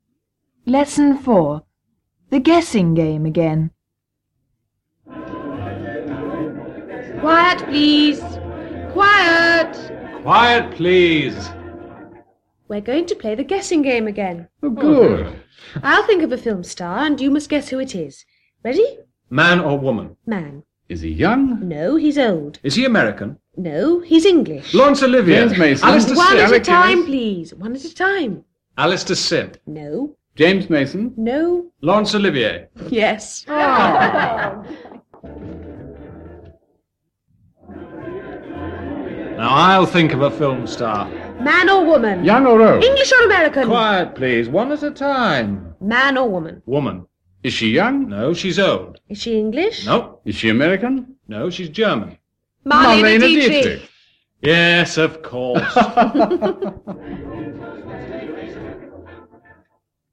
Lesson four: